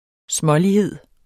Udtale [ ˈsmʌliˌheðˀ ]